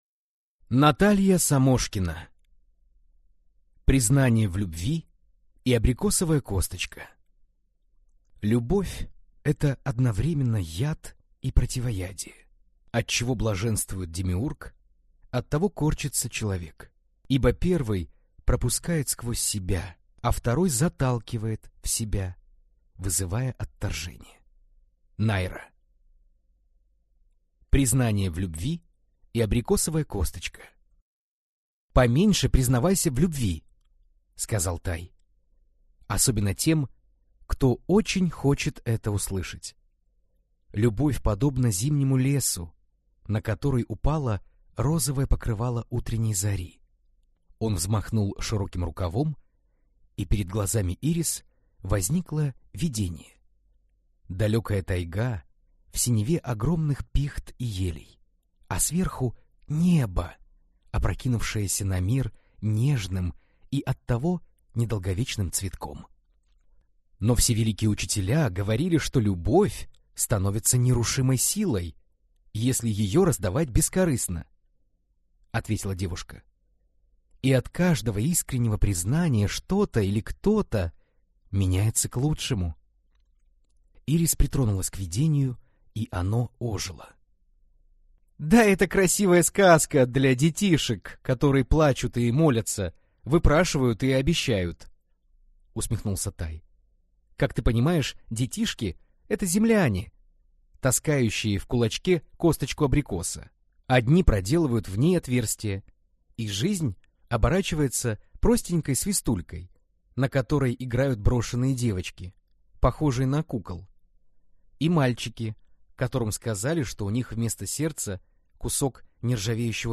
Аудиокнига Признание в любви и абрикосовая косточка | Библиотека аудиокниг